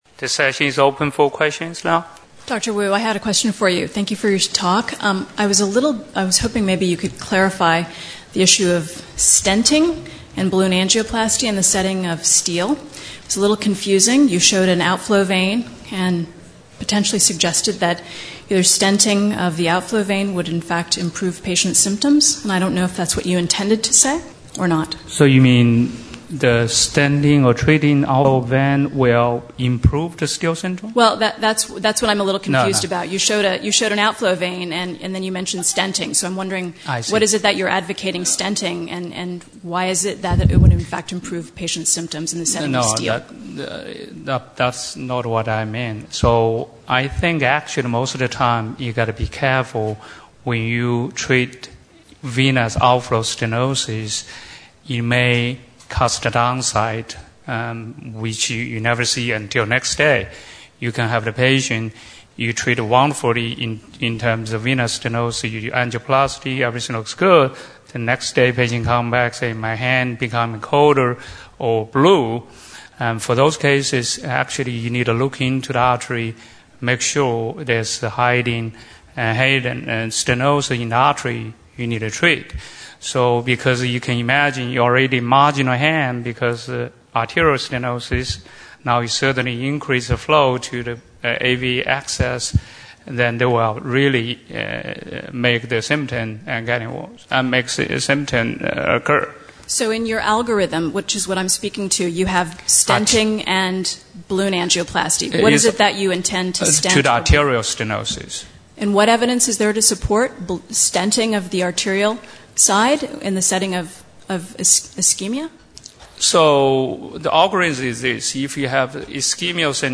HDCN - 2012 ASDIN Annual Scientific Meeting
Discussions